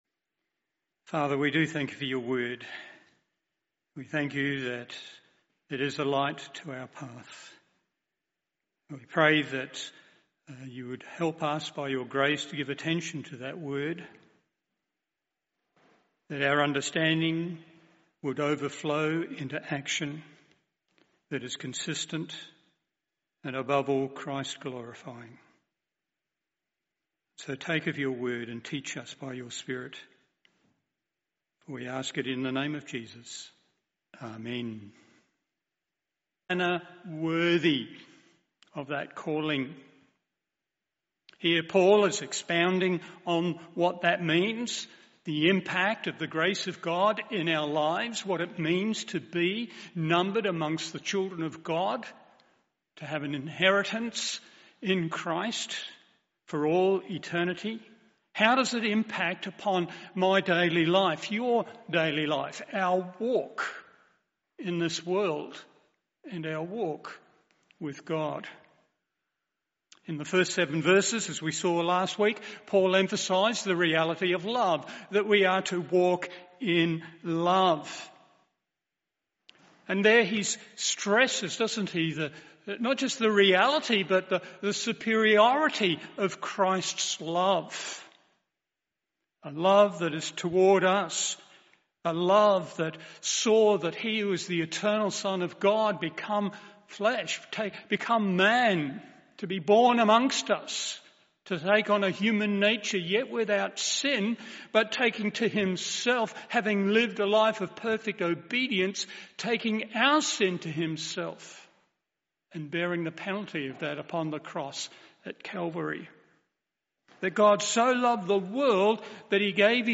Slight cutoff at around 30 second mark due to technical issues Eph 5:8-14…
Morning Service